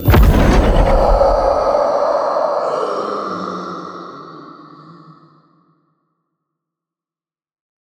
ghost_blowout.ogg.bak